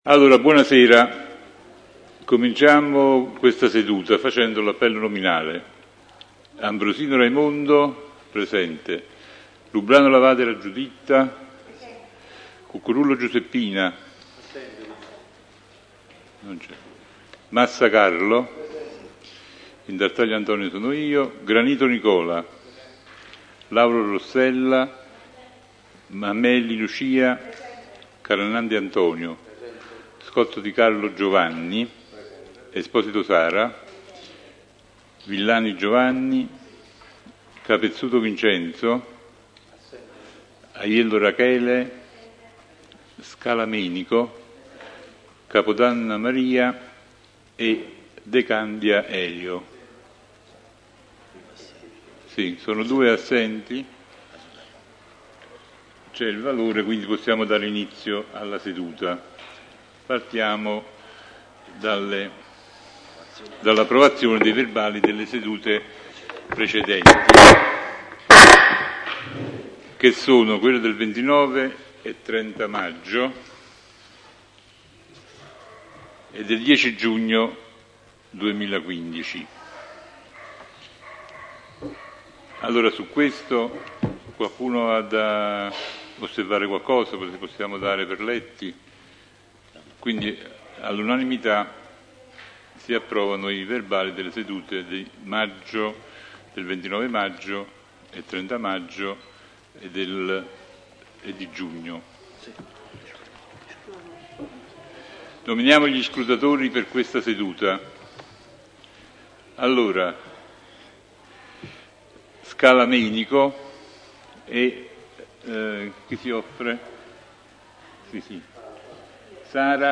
Procida: Consiglio comunale del 29 luglio 2015 - Il Procidano